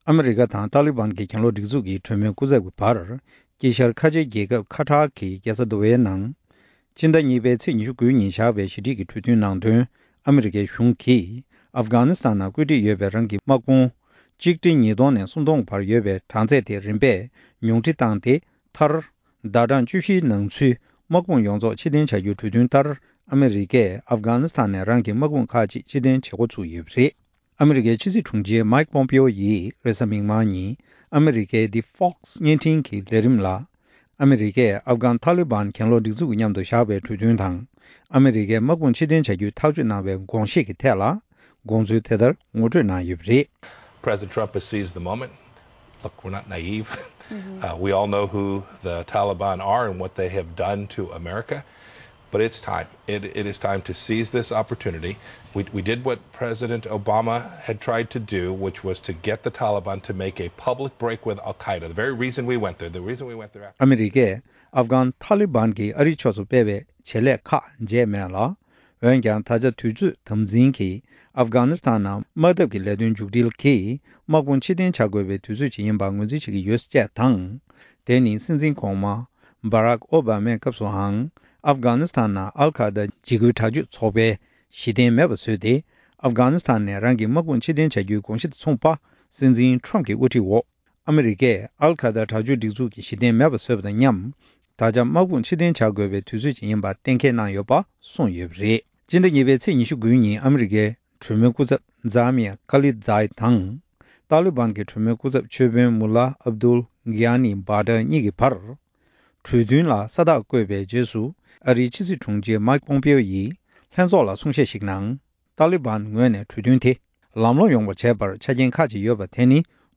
ཨ་མི་རི་ཀས་ཨབ་གྷན་ནིས་ཐན་ནས་དམག་དཔུང་ཁག་ཅིག་ཕྱིར་འཐེན་བྱེད་སྒོ་བཙུགས་པ། སྒྲ་ལྡན་གསར་འགྱུར།